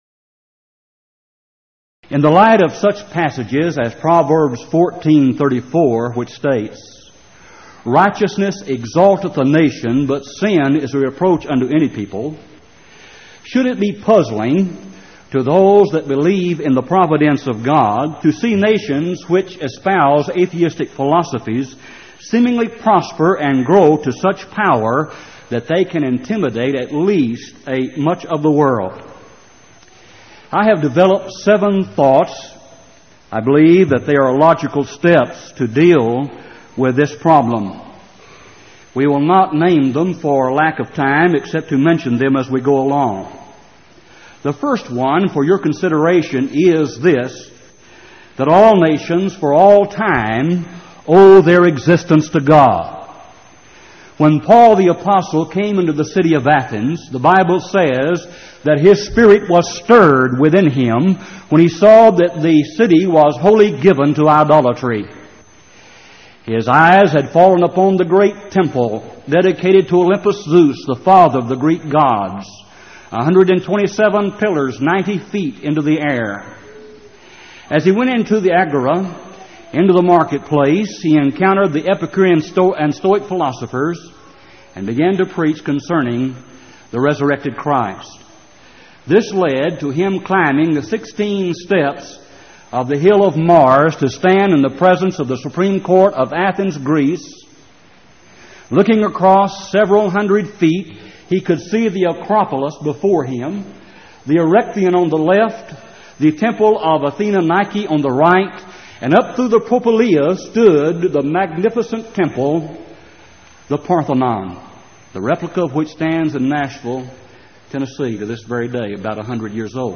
Event: 1989 Power Lectures
lecture